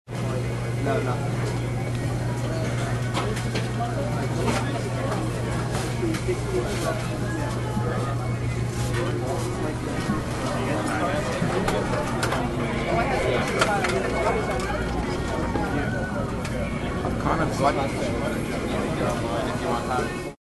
Фоновые шумы посуды, разговоров и музыки помогут воссоздать атмосферу заведения или использовать их для творческих проектов.
Звуки кофейни, бара, столовой, кафе и других мест: атмосферный интершум в кафе или забегаловке